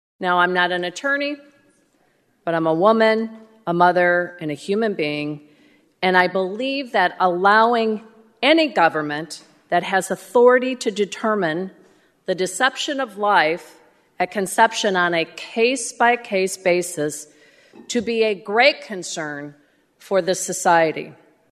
The Senate’s Republicans say the bill’s aimed at silencing abortion opponents. Sue Rezin spoke against it on the Senate floor.